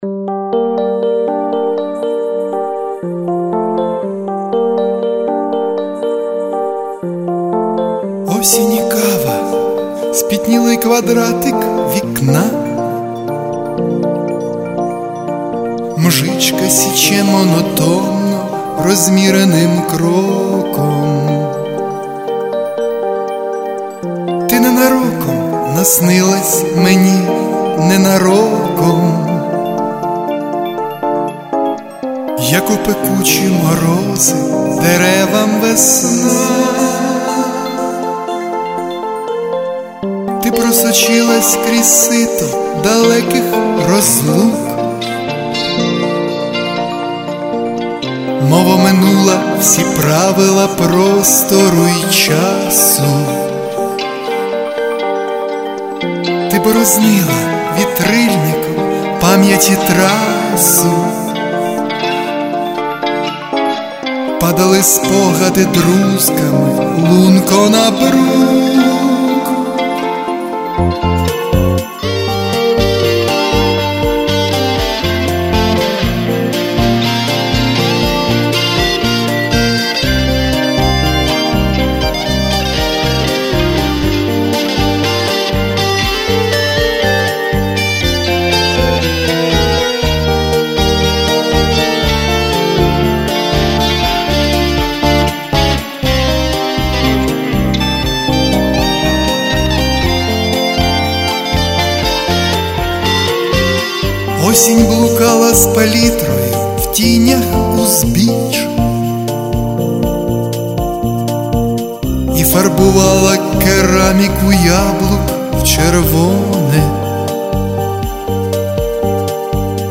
Пісня
запис і виконання (від чоловічого імені)